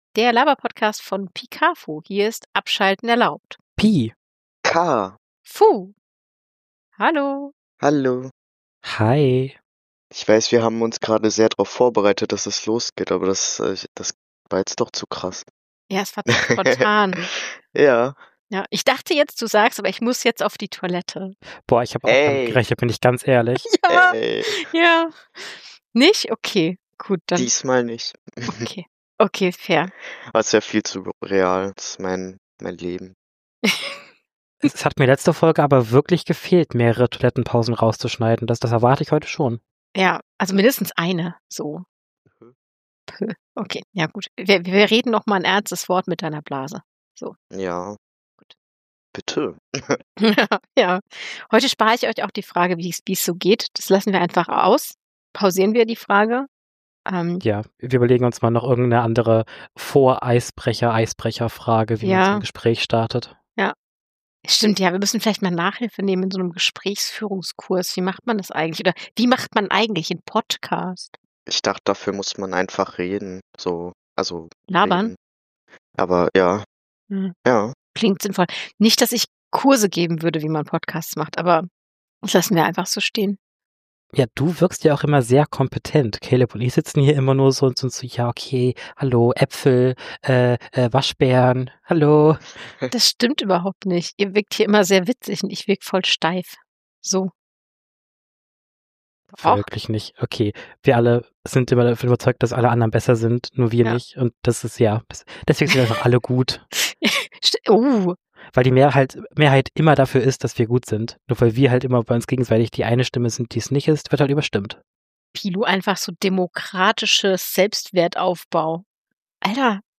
allerdings mit musikalischen Einlagen.